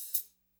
Perc (41).WAV